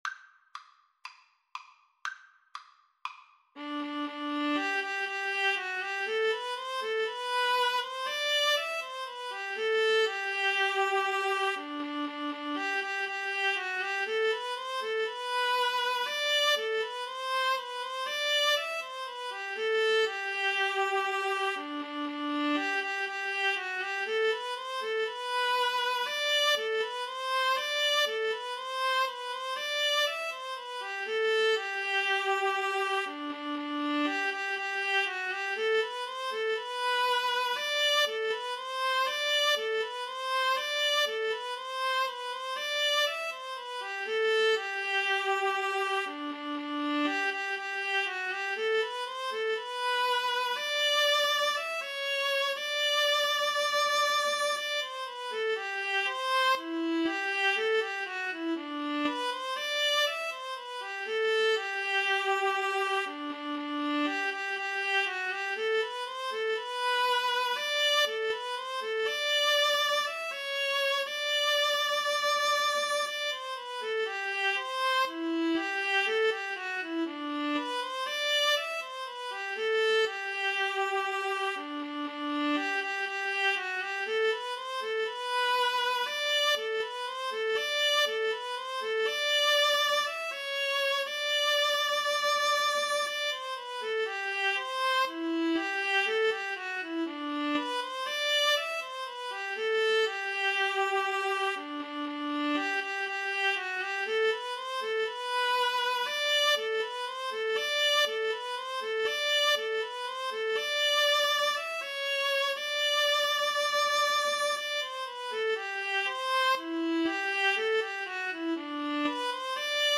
Free Sheet music for Viola-Cello Duet
G major (Sounding Pitch) (View more G major Music for Viola-Cello Duet )
Allegro (View more music marked Allegro)
4/4 (View more 4/4 Music)
Traditional (View more Traditional Viola-Cello Duet Music)